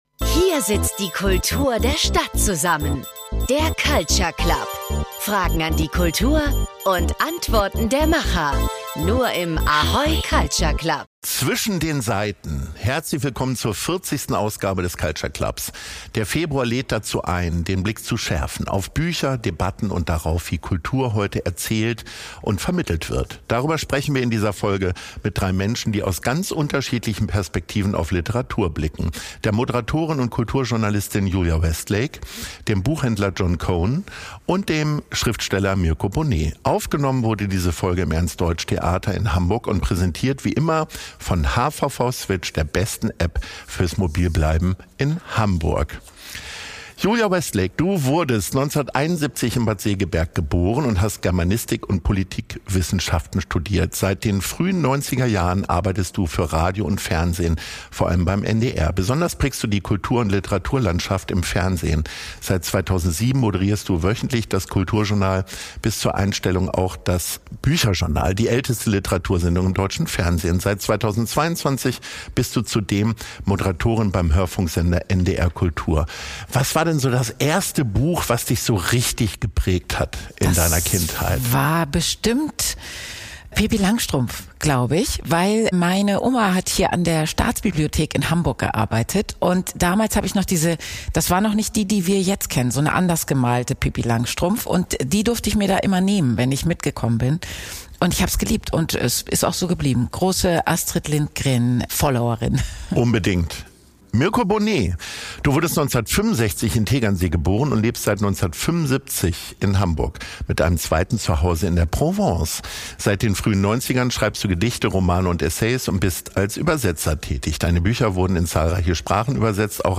Sie sprechen über ihre Kindheitslieblingsbücher, darüber, was sich in der Hamburger Kulturszene verändern muss, und darüber, wie sie selbst lesen und schreiben. Aufgenommen wurde diese Folge im Ernst Deutsch Theater in Hamburg und präsentiert wie immer von HVV Switch, der besten App fürs Mobilbleiben in Hamburg.